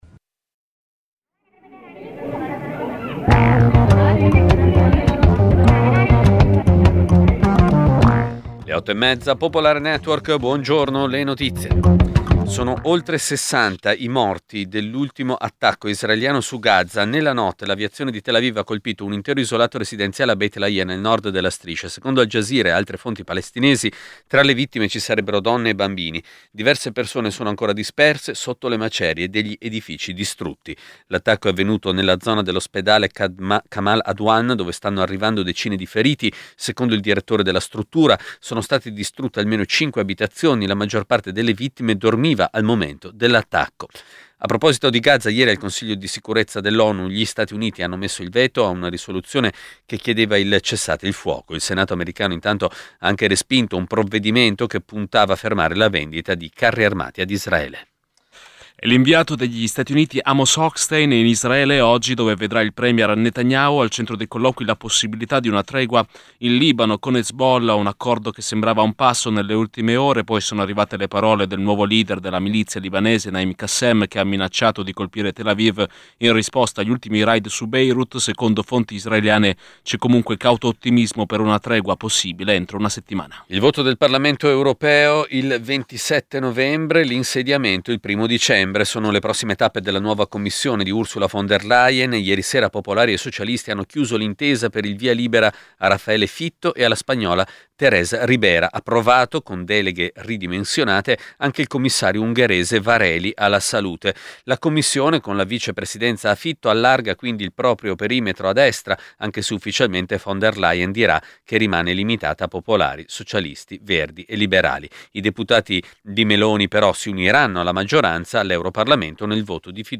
Giornale radio nazionale - del 21/11/2024 ore 08:30